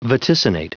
Prononciation du mot vaticinate en anglais (fichier audio)
Prononciation du mot : vaticinate